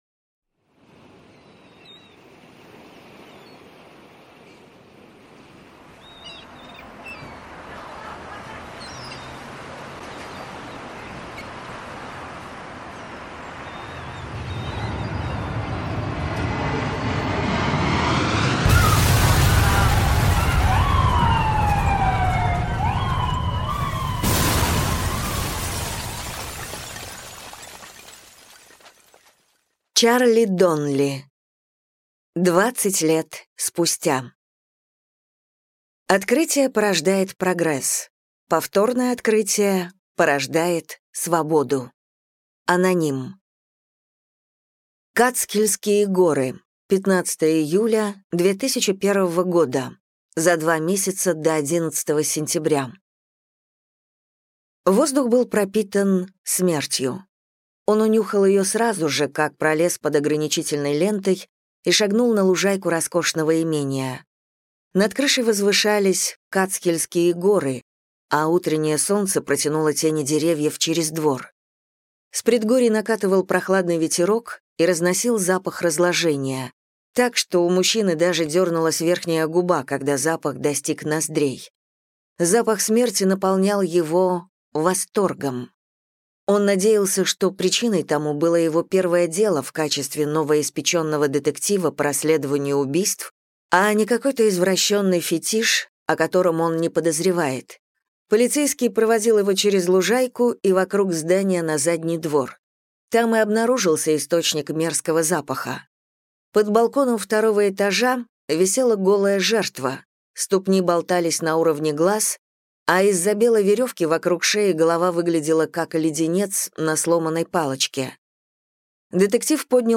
Аудиокнига Двадцать лет спустя | Библиотека аудиокниг